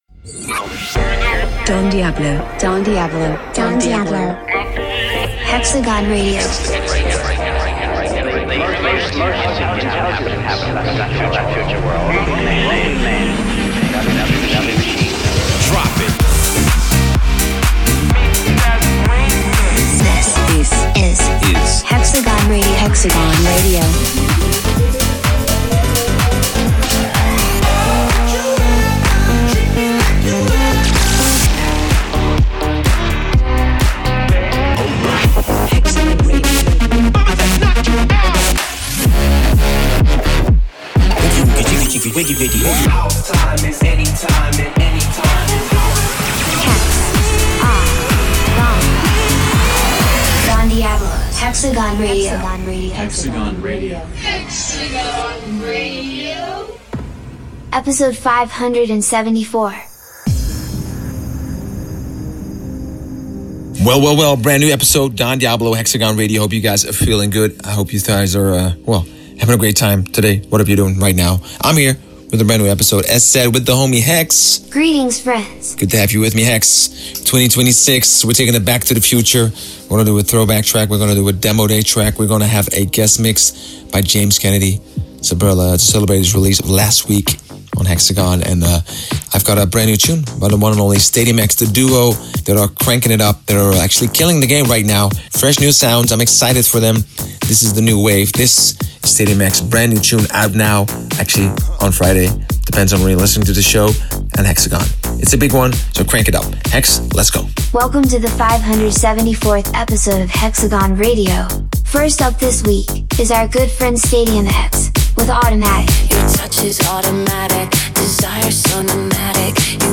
Also find other EDM Livesets, DJ Mixes